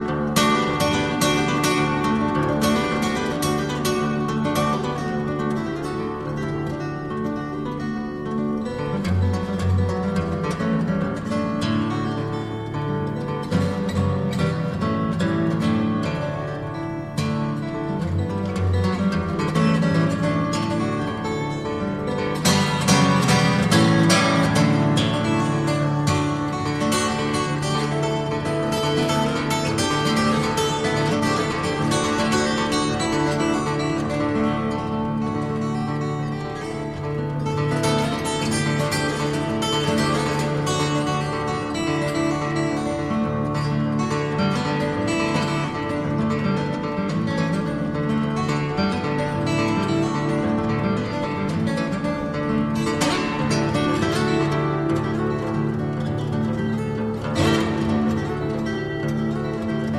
Рок
"кислотная" гитара и глубокий, потусторонний вокал